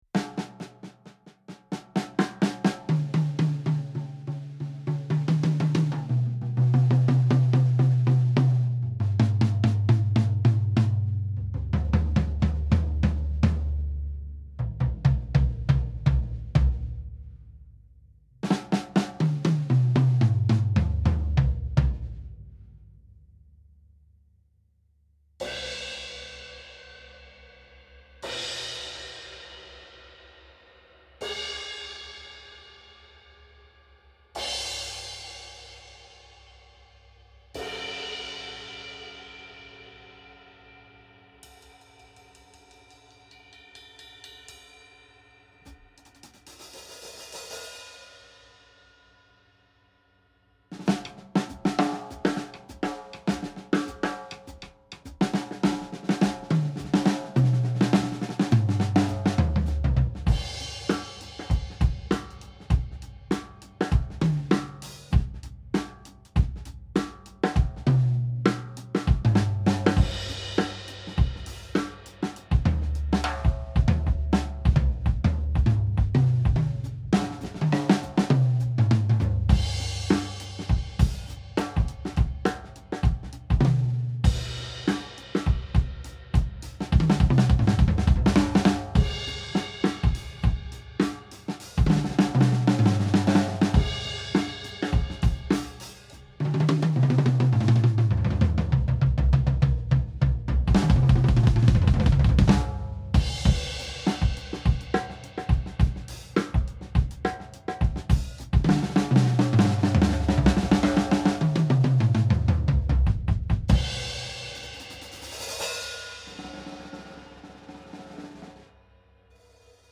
Pieni testiäänitys Sakae-kioskista viiden tomin kera:
Mikityksenä vain bd+snare+overit ja tila stereona, ei erillisiä tomimikkejä.
sakae5tom.mp3